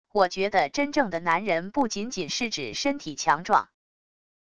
我觉得真正的男人不仅仅是指身体强壮wav音频生成系统WAV Audio Player